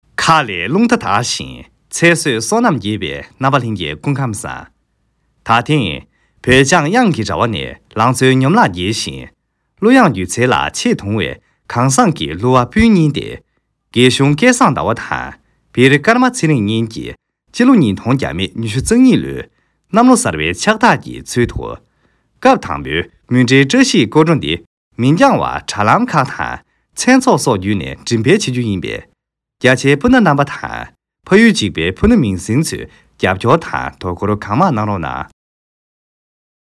拉萨藏语-电台主播